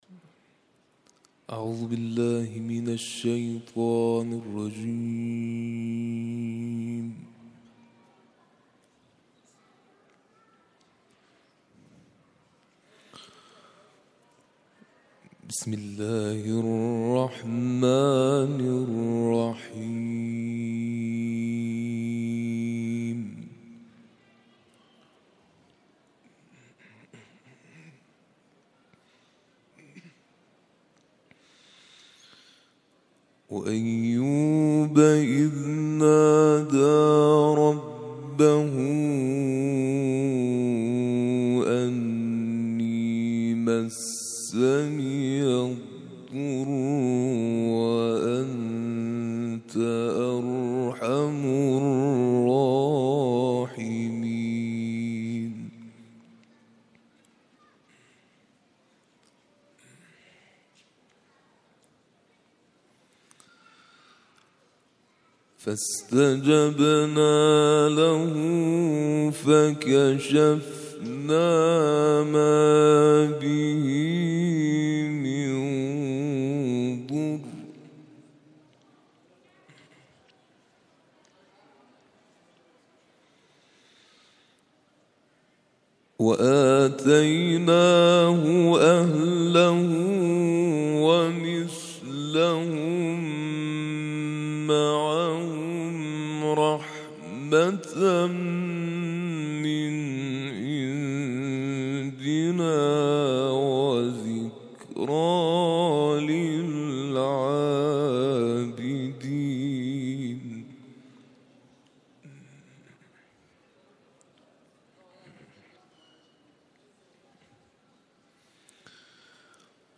تلاوت مغرب
تلاوت قرآن کریم